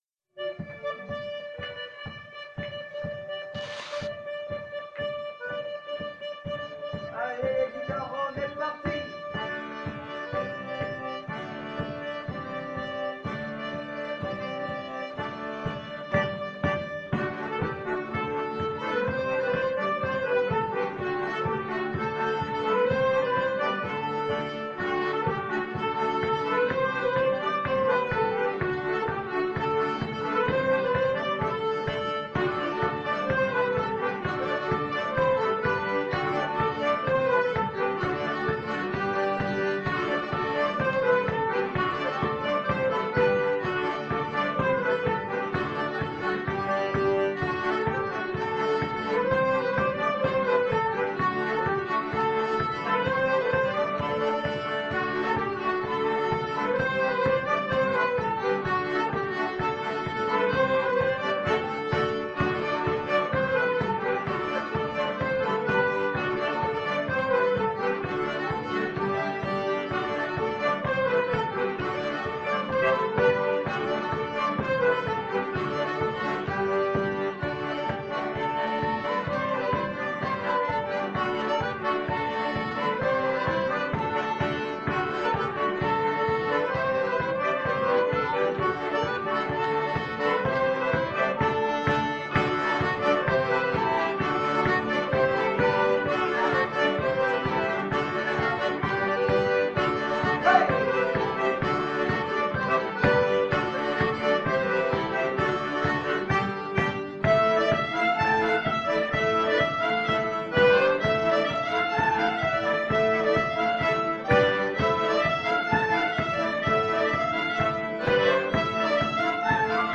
la musique d'ensemble d'Arpalhands réunit accordéons diatoniques, violons, guitares, flûtes, clarinettes, percussions.
Filage integral 17 mai.mp3